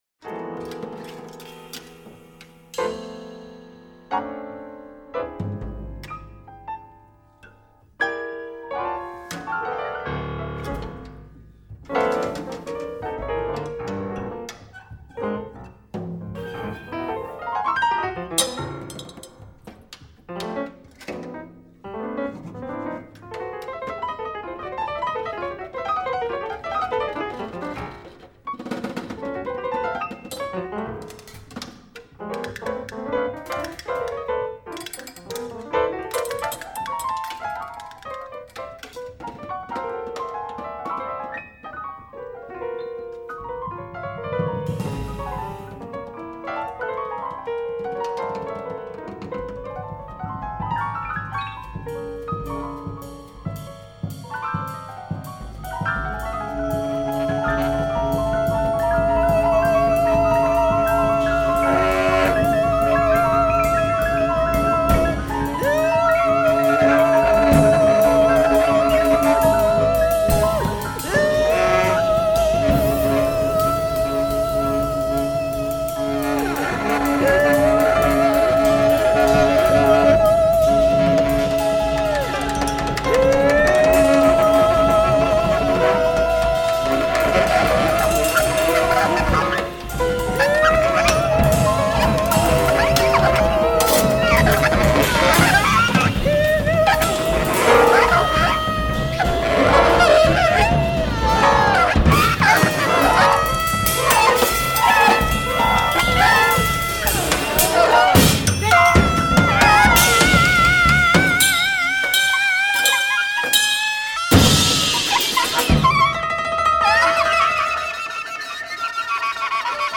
free improvised music